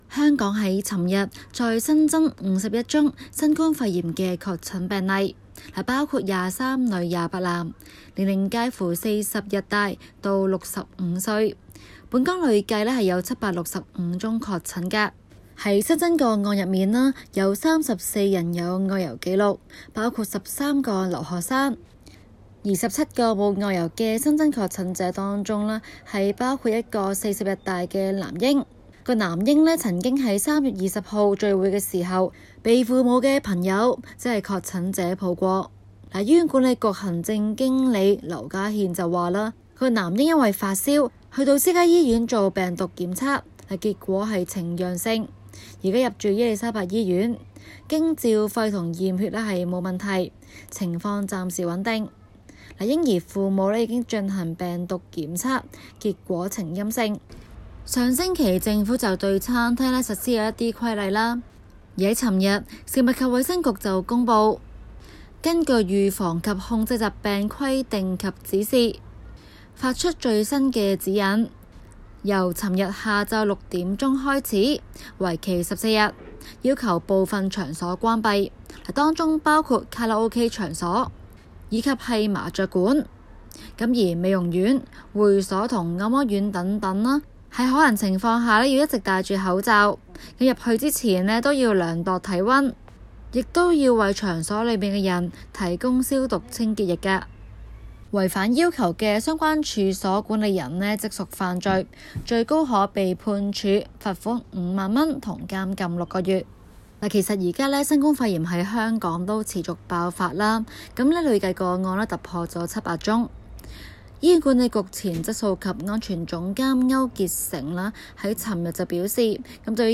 今期【中港快訊】環節報導香港最新感染個案再多添51宗，其中34宗有外遊記錄，累積個案上升至765宗。